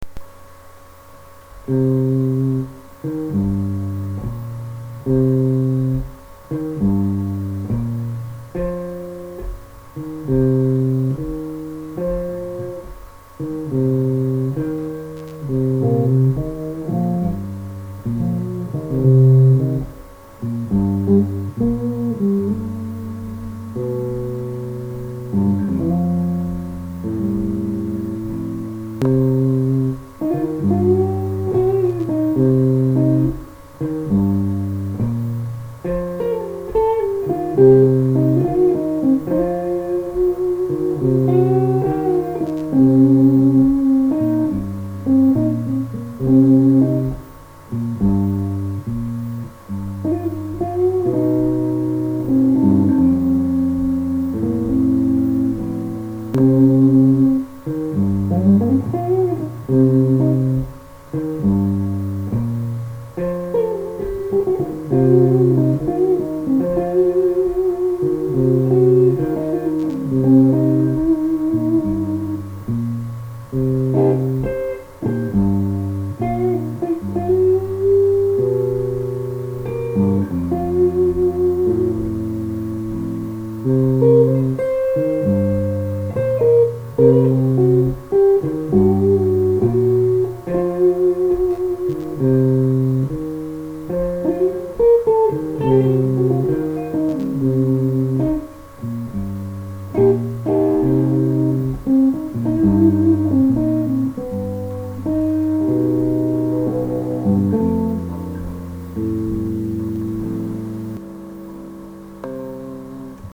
J'ai enregistré un début de blues.
Désolé pour la qualité du son et pour la pseudo fin (je l'ai coupée car il y a eu une belle fausse note et des nom d'oiseaux)
En langage normal, elle est pas mal portée vers un son rond grave médium.